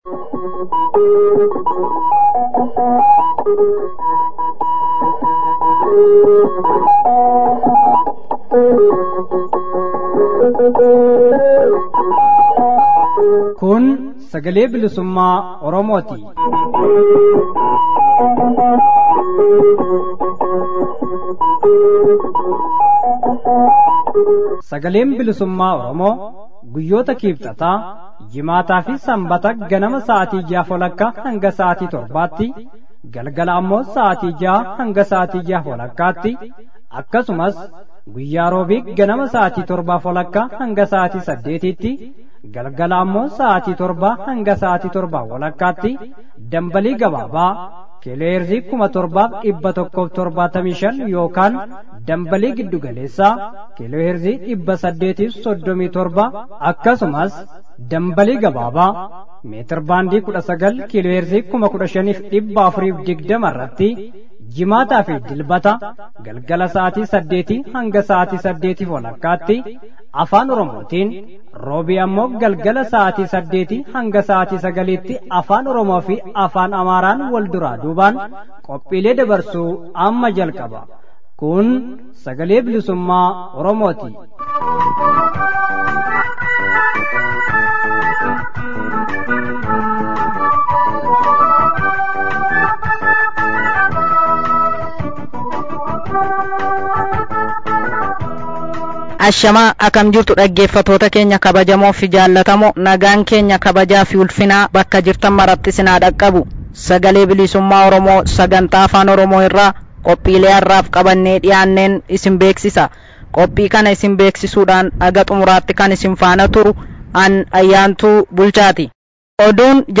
SBO: Waxabajjii 28 bara 2017. Oduu fi Gaaffii fi Deebii